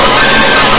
BUZZSAW.mp3